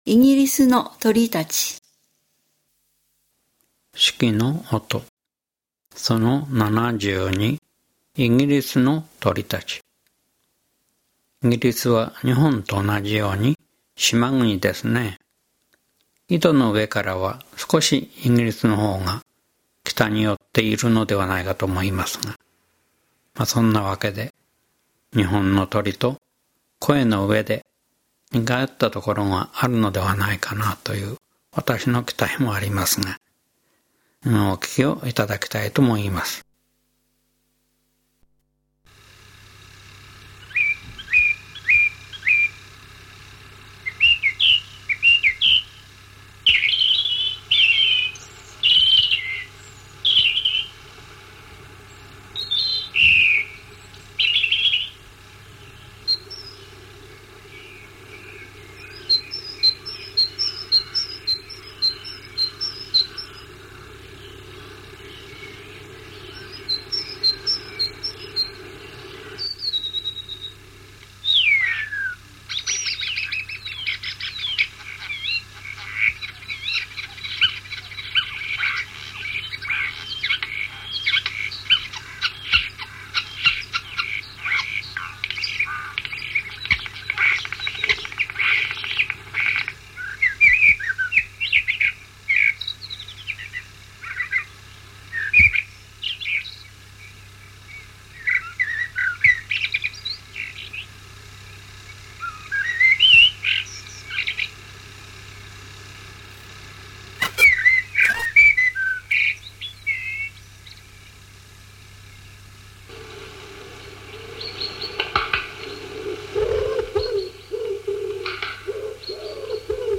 鳥の声
イギリスの鳥